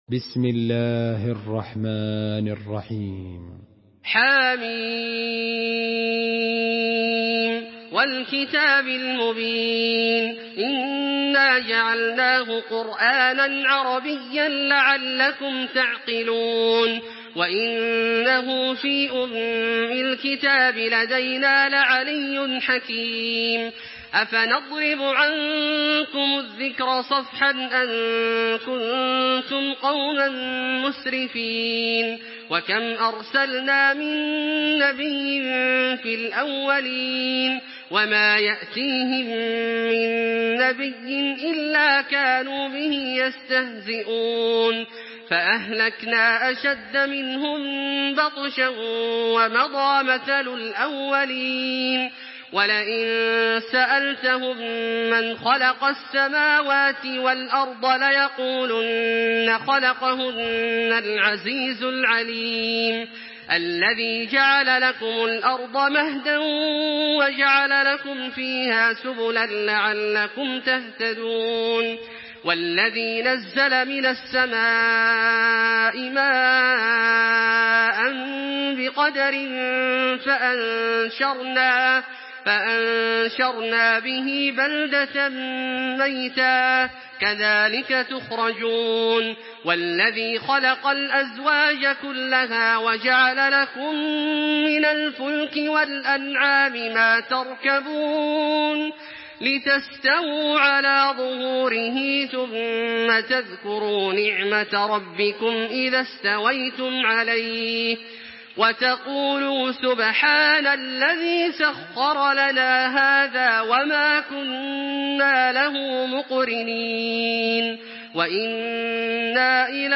Surah Zuhruf MP3 in the Voice of Makkah Taraweeh 1426 in Hafs Narration
Murattal Hafs An Asim